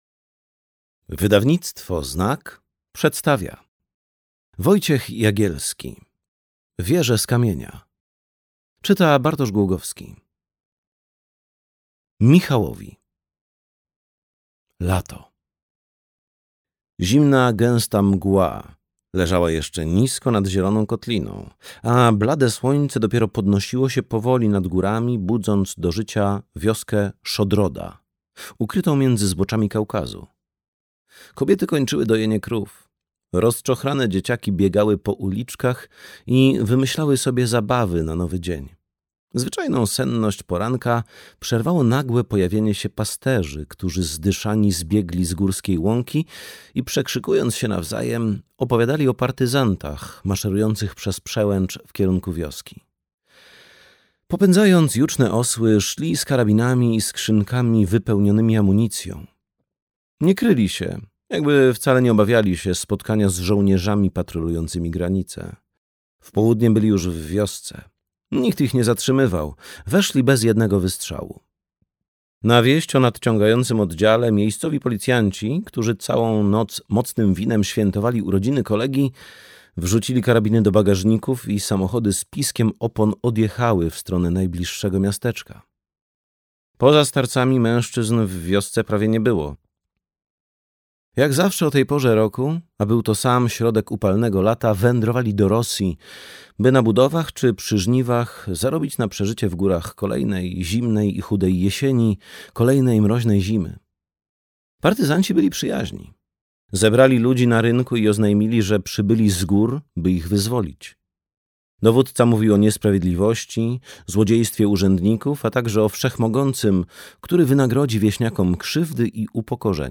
Wieże z kamienia - Wojciech Jagielski - audiobook